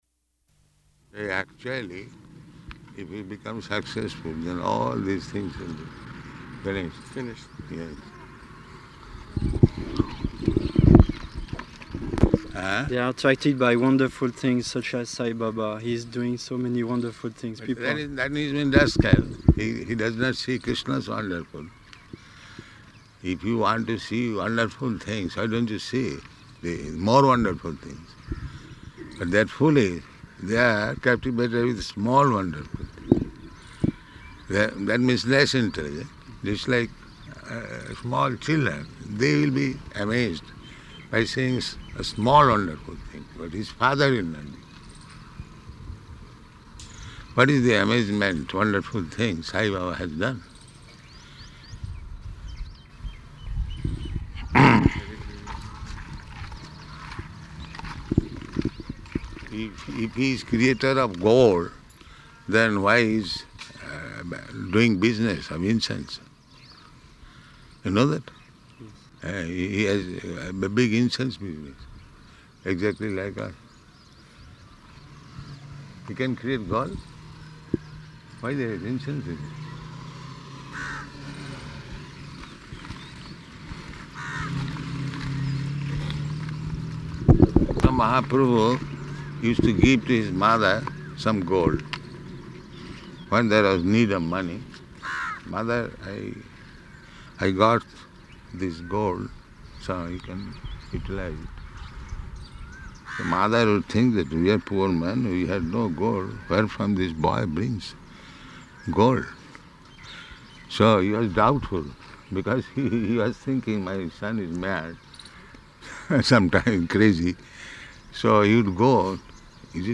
Morning Walk --:-- --:-- Type: Walk Dated: April 29th 1974 Location: Hyderabad Audio file: 740429MW.HYD.mp3 Prabhupāda: Actually, if we become successful then all these things will be finished.